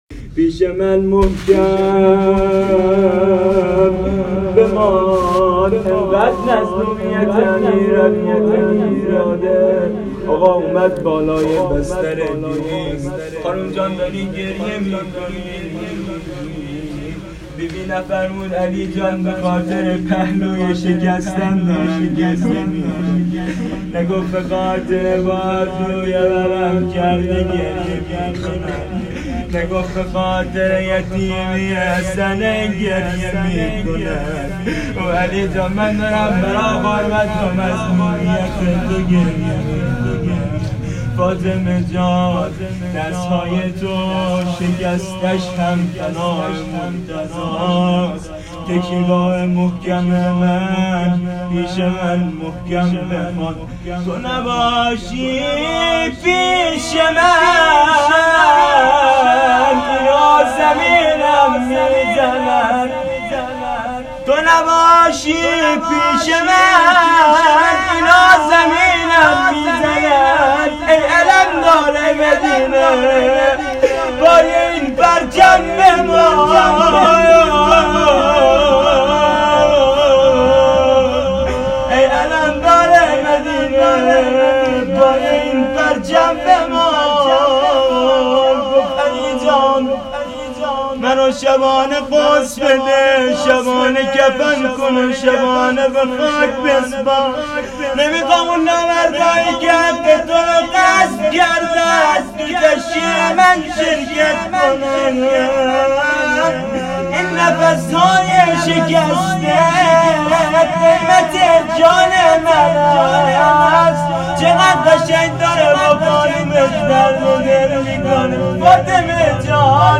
اجرا شده در حسینیه ی زینبیون هیئت انصارالموعود فاطمیه سال 98